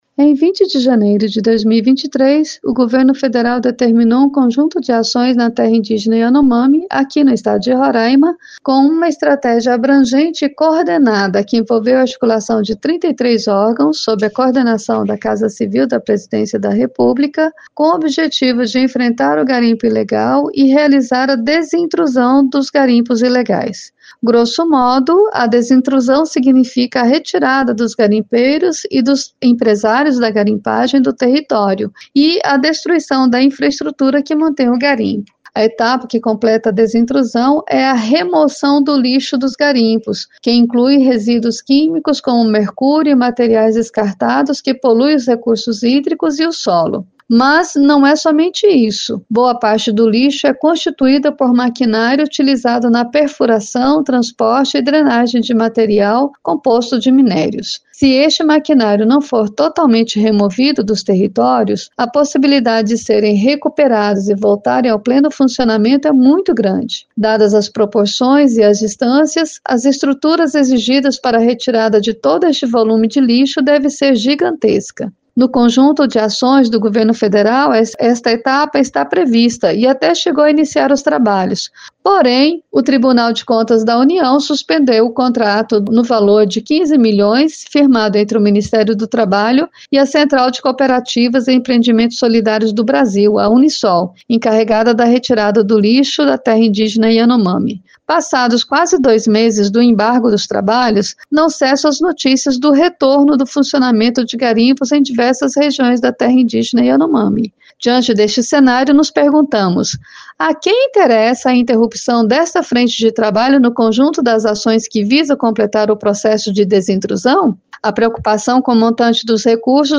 Neste editorial